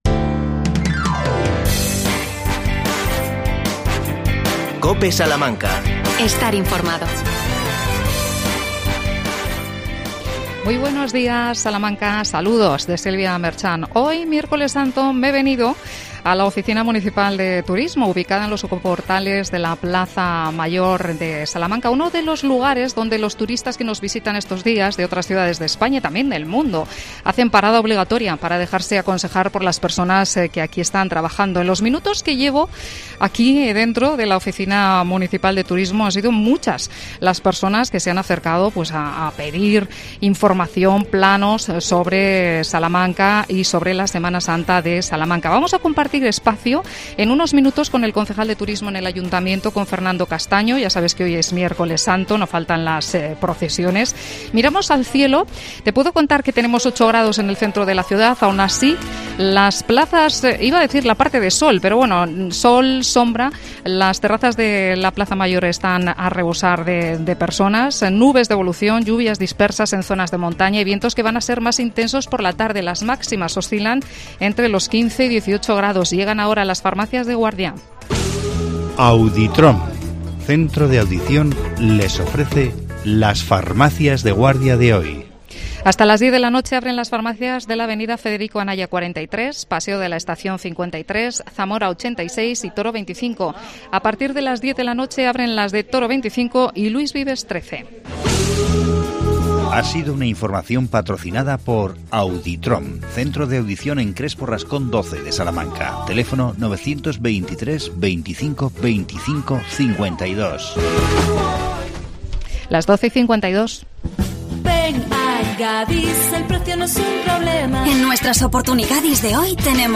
AUDIO: Entrevistamos a Fernando Castaño, concejal de Turismo, en la Oficina de Turismo